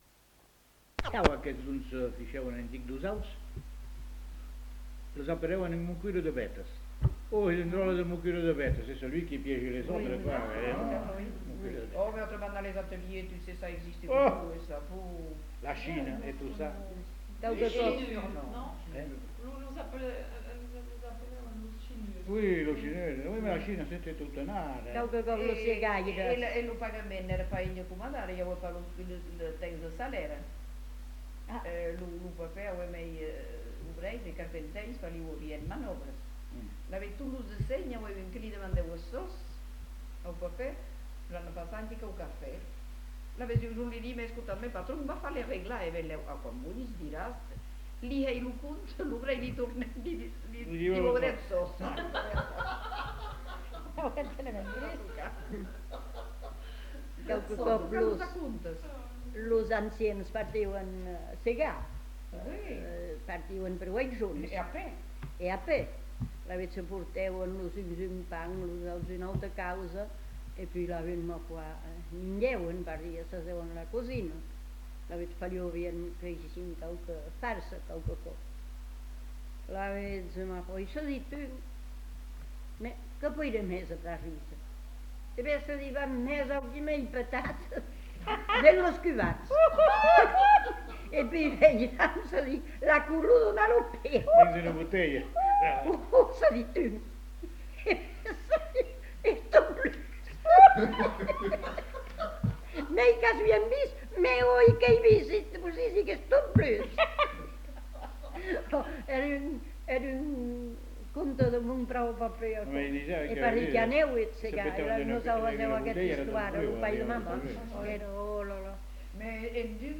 Récits d'anecdotes et de souvenirs
Genre : parole